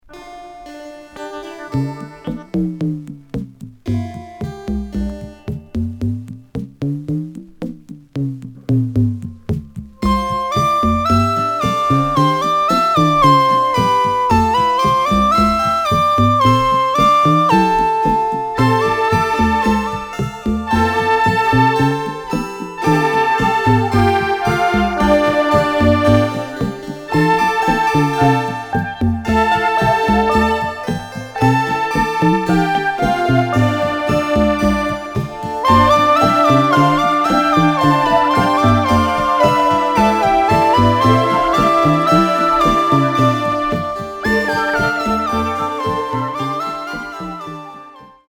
キーワード：電子音　コラージュ　ミニマル